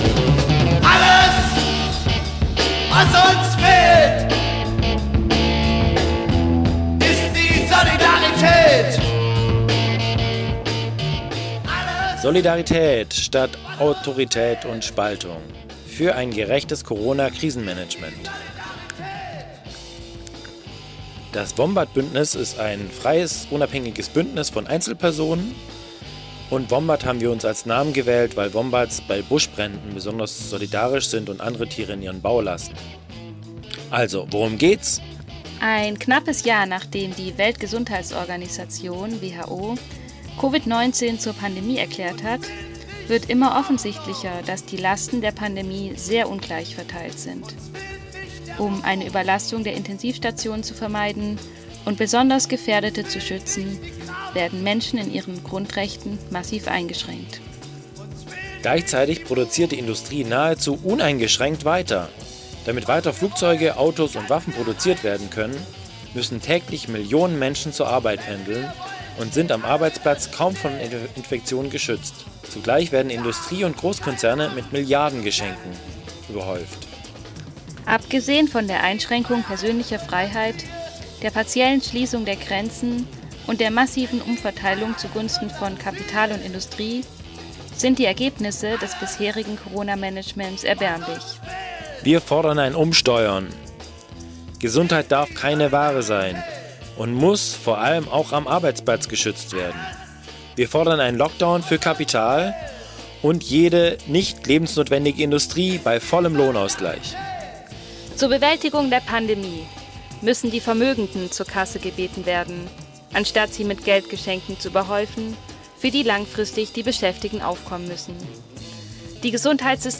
Jingle für die Demo am 13.3. 3:28min